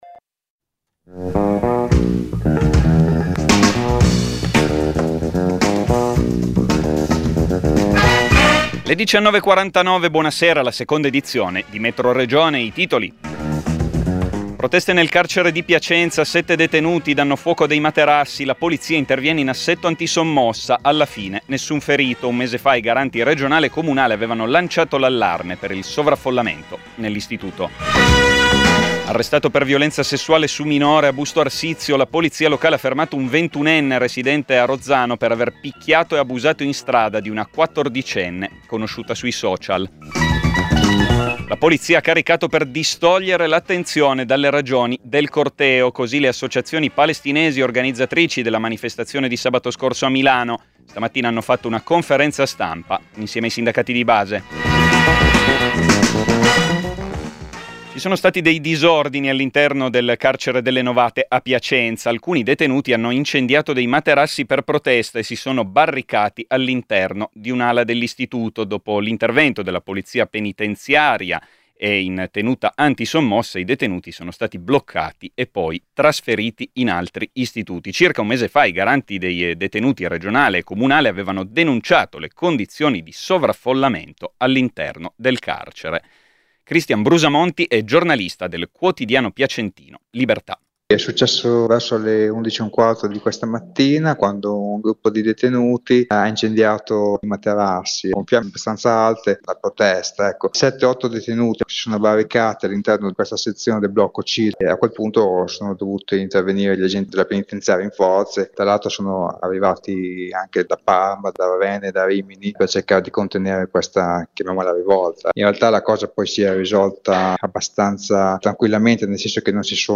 Conferenza stampa con gli organizzatori del corteo proPal - CUB